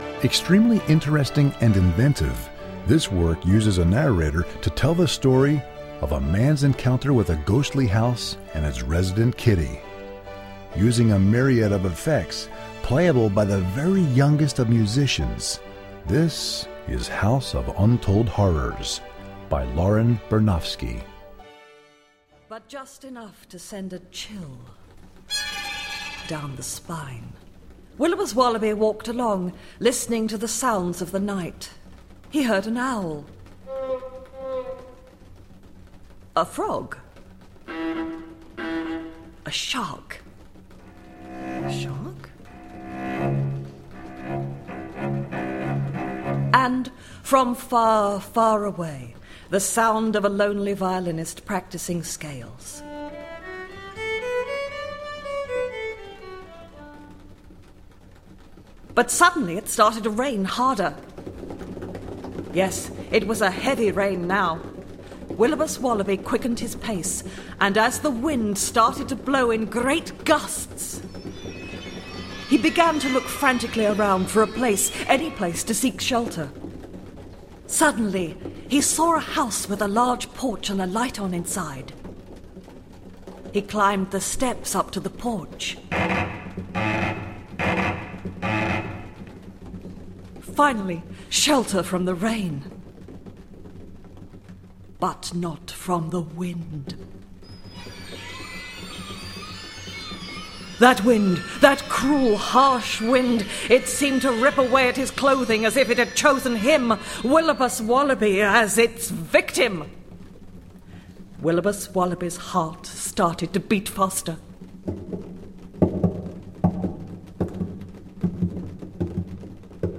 for Narration and String Orchestra
Arranger: Narrator and Orchestra
Voicing: String Orchestra